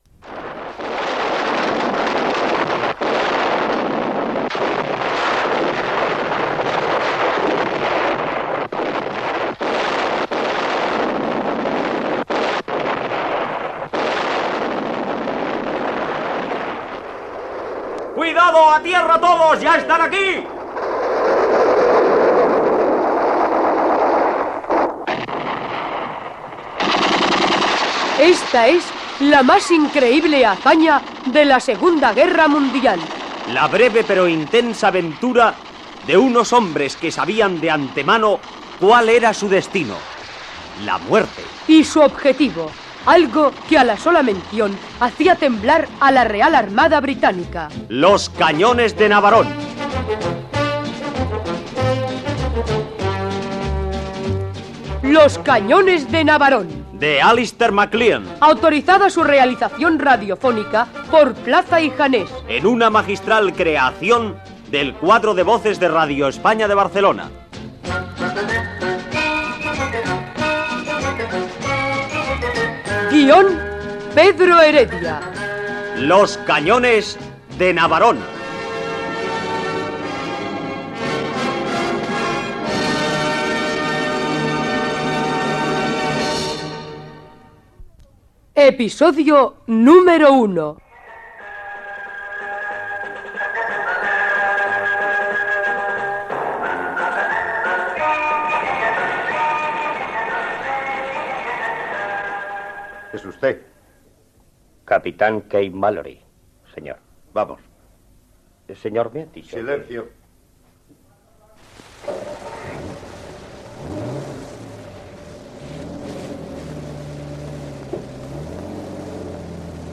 Primer l'episodi. Diàleg entre el cap de guerrillers i el capità. Careta de sortida
Ficció